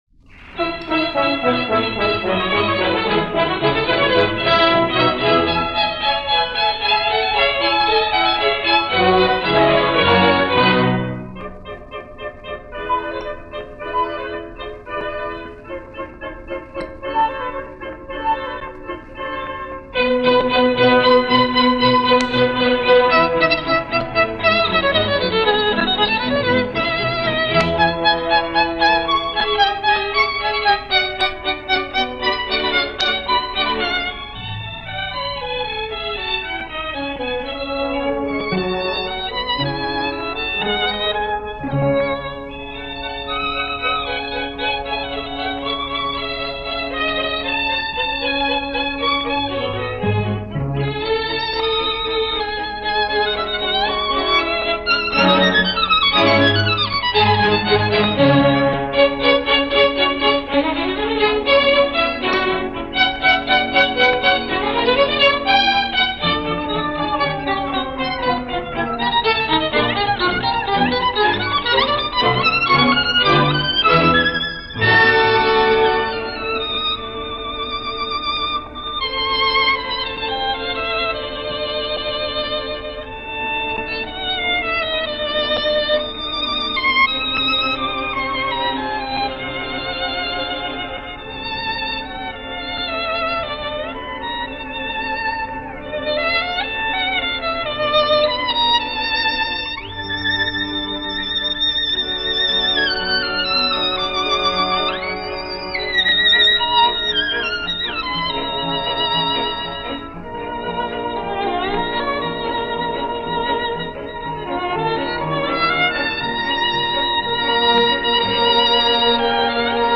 Violin Concerto
French National Orchestra
Paris Radio
radio broadcast performance
Arrieu-Violin-Concerto.mp3